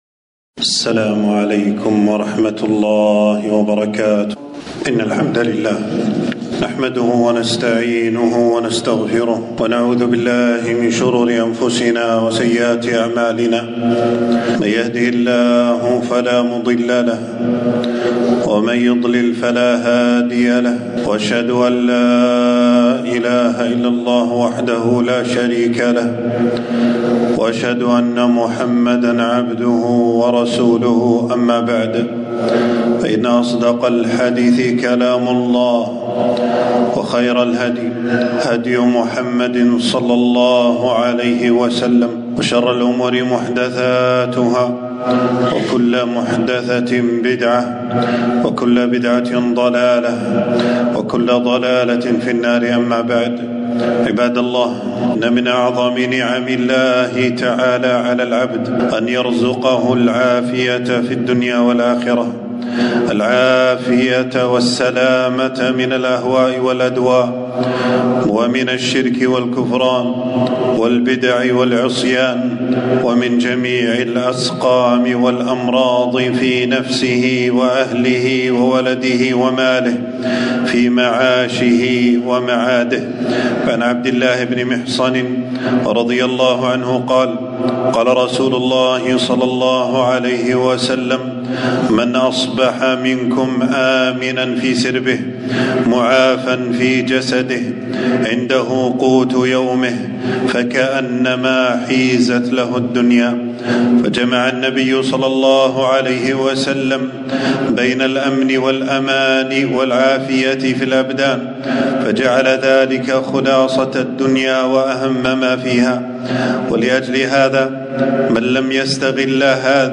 خطبة - نعمة العافية